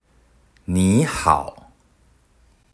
「你（nǐ）」も「好（hǎo）」も三聲なのですが、三聲が２つ続くと前側の漢字のイントネーションが二聲に変化するルールがあるので、「你（ní）」と語尾を上げるように発音。
▼「你好（こんにちは）」発音サンプル
※発音サンプルは、中国語ネイティブの台湾人の方にお願いしました。